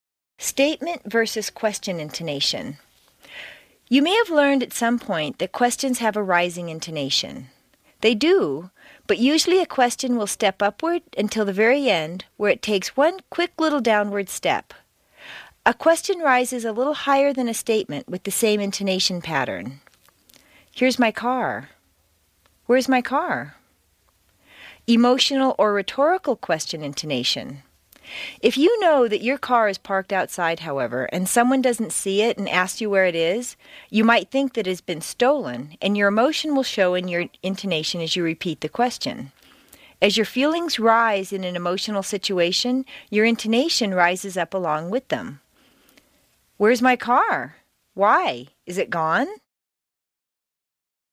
美式英语正音训练第53期:陈述句语调与疑问句语调的对比 听力文件下载—在线英语听力室
在线英语听力室美式英语正音训练第53期:陈述句语调与疑问句语调的对比的听力文件下载,详细解析美式语音语调，讲解美式发音的阶梯性语调训练方法，全方位了解美式发音的技巧与方法，练就一口纯正的美式发音！